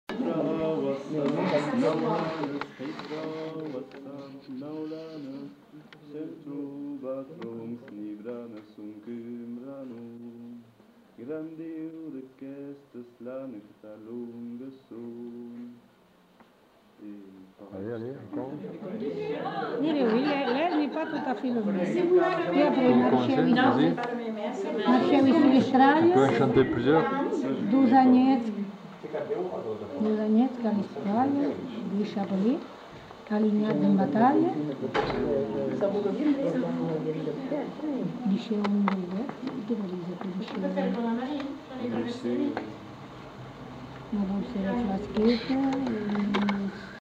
Lieu : Allons
Genre : chant
Effectif : 1
Type de voix : voix d'homme
Production du son : chanté
Classification : chansons de neuf